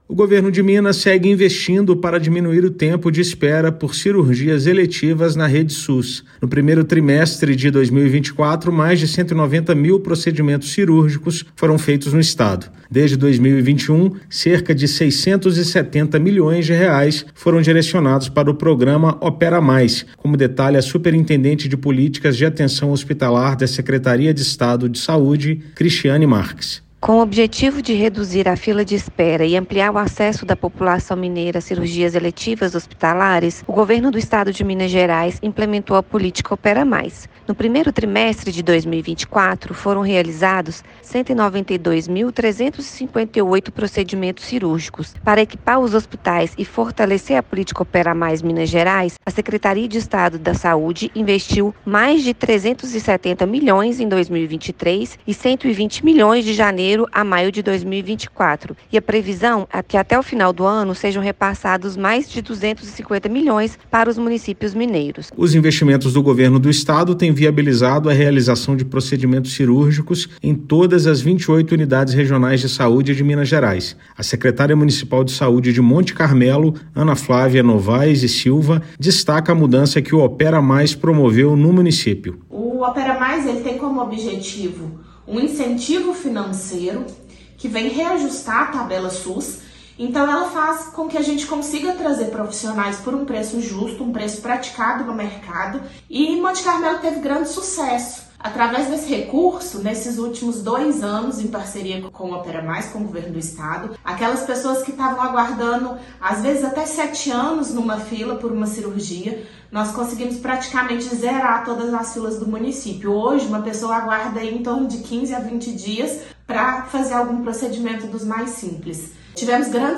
Com R$ 670 milhões de investimentos, Secretaria de Saúde expande a oferta de procedimentos cirúrgicos na rede SUS; mais de 190 mil cirurgias eletivas já foram realizadas no primeiro trimestre de 2024. Ouça matéria de rádio.